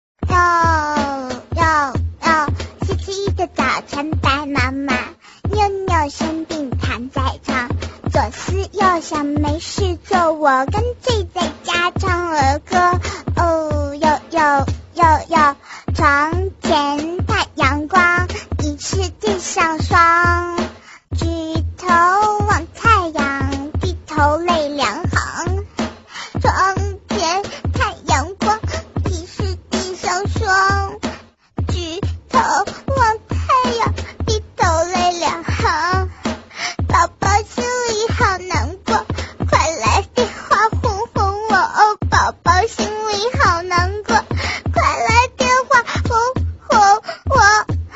搞笑铃声